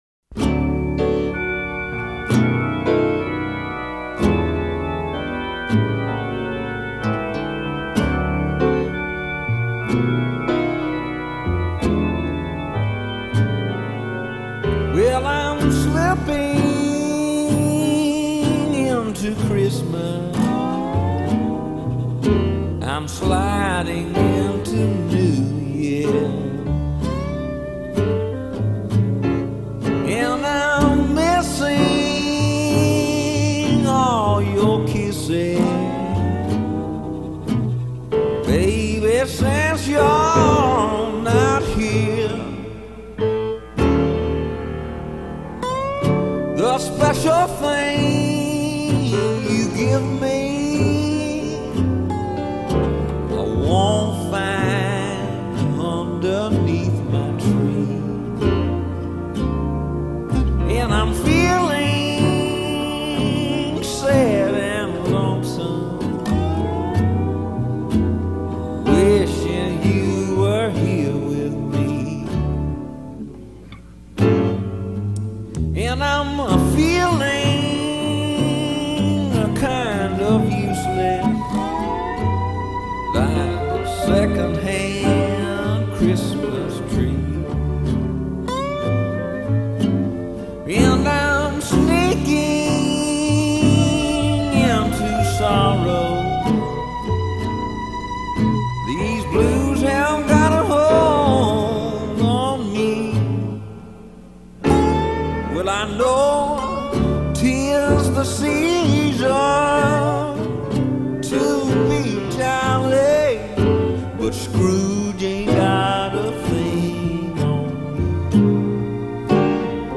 a single released in 1972